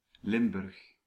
Ääntäminen
IPA: /ˈlɪm.bʏrx/